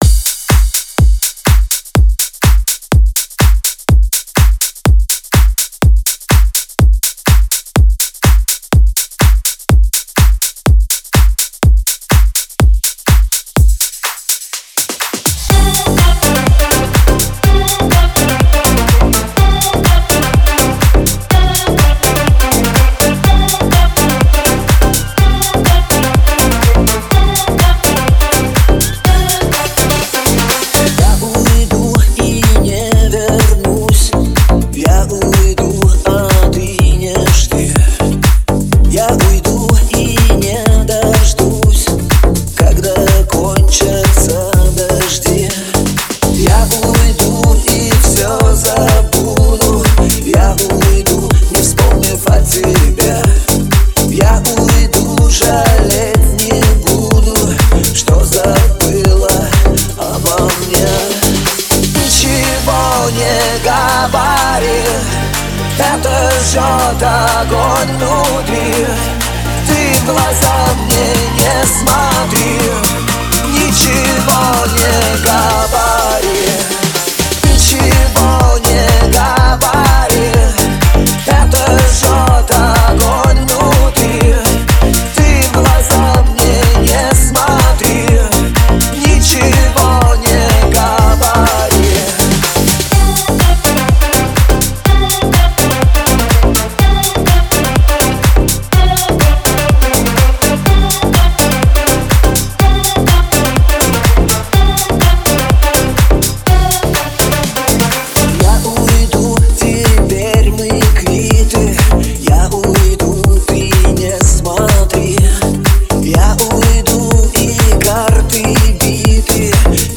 Жанр: Pop, Dance, Other